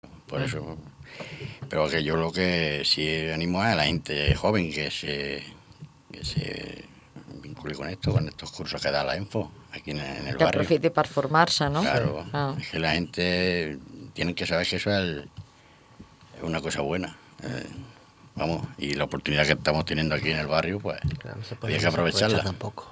Avui volem compartir amb vosaltres un tall del programa “Posa’t les piles” en el qual van col·laborar participants del curs d’auxiliar de magatzem del programa Treball als barris.